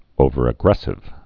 (ōvər-ə-grĕsĭv)